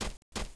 monster / barbarian_bow / foot_1.wav
foot_1.wav